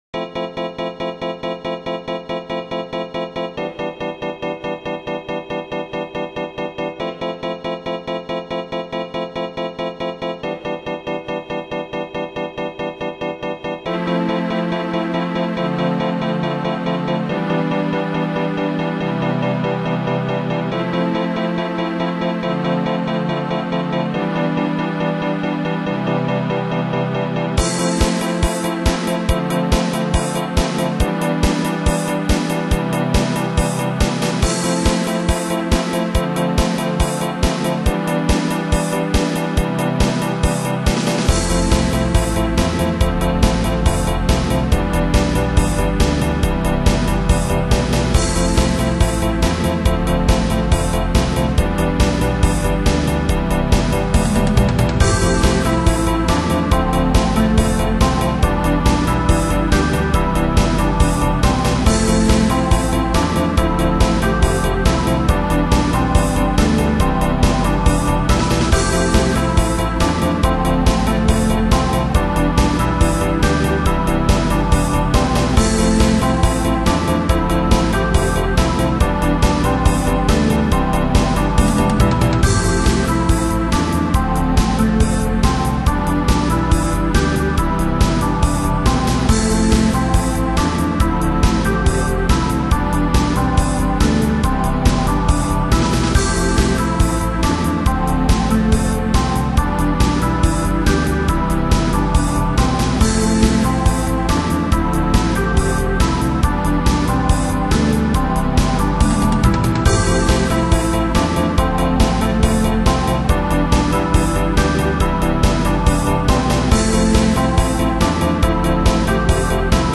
ロック ８ビートと和風の音階にこだわって作ってみたら、
どことなくテリヤキ風味（？）な曲になってしまいました。